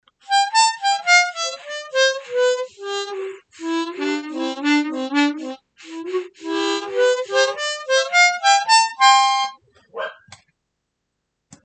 If I play the 1-2 notes “super carefully” they sound almost ok, but they have this raspy quality to them: